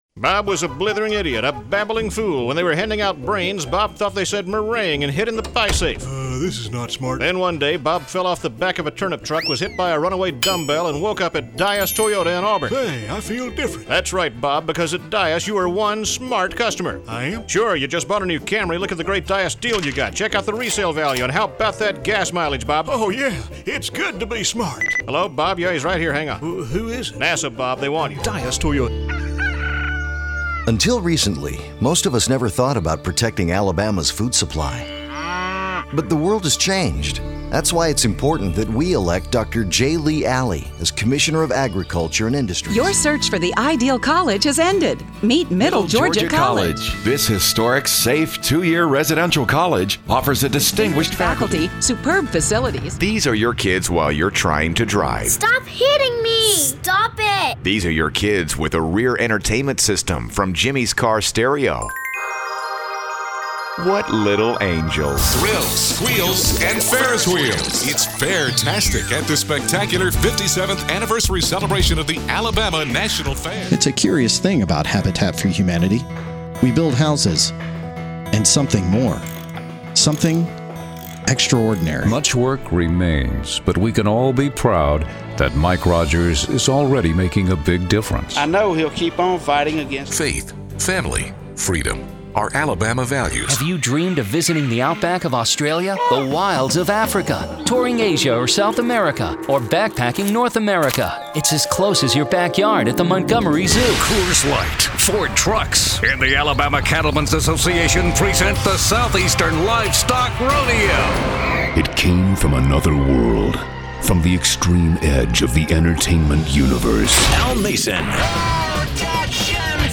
DEMOS
Our commercial clients range from banks to bars, from car dealers to carwashes and from plumbers to politicians. Which, if nothing else, makes for a pretty interesting mashup: